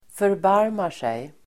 Ladda ner uttalet
Uttal: [förb'ar:mar_sej]